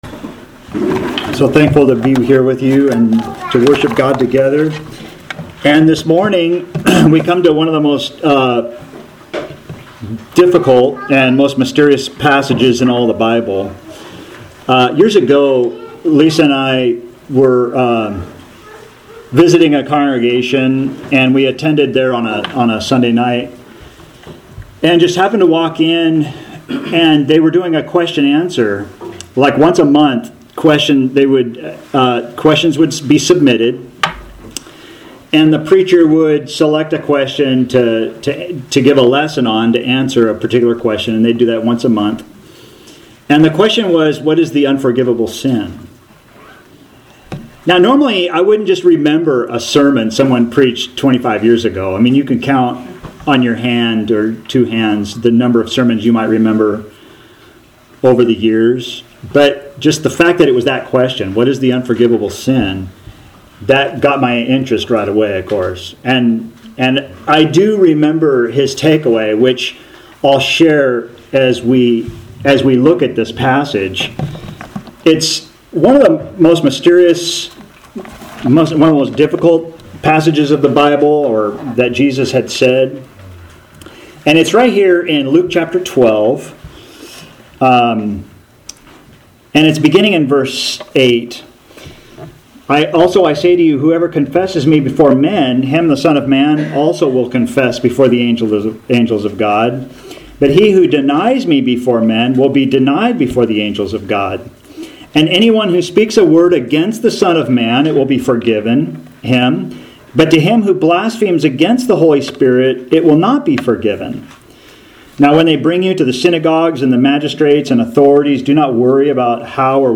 What is the unforgivable sin? In this lesson we seek to understand what Jesus said in one of the most difficult passages of the Bible.